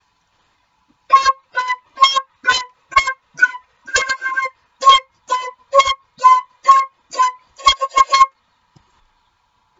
alarm2.m4a